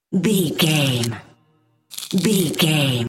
Creature eating flesh peel short
Sound Effects
scary
ominous
disturbing
eerie